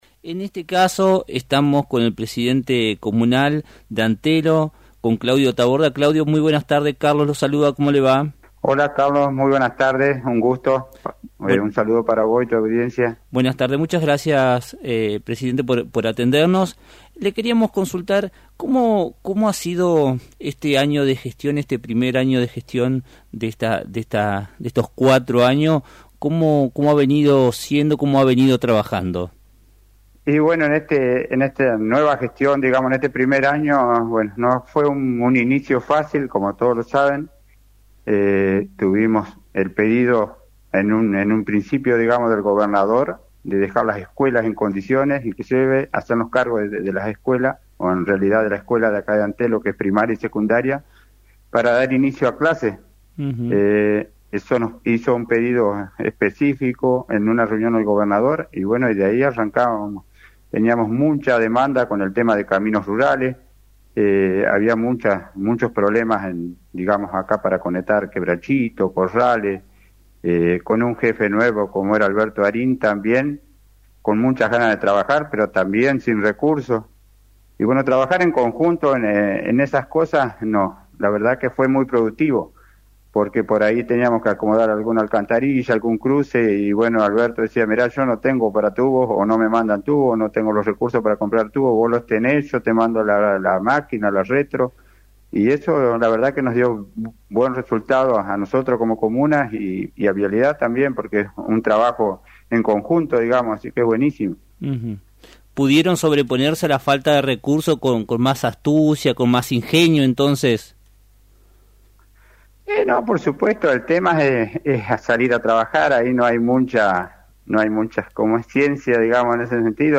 El presidente de la Comuna de Antelo, Claudio Taborda, compartió en Lt39 los avances y desafíos de su primer año de gestión.